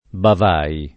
[ bav # i ]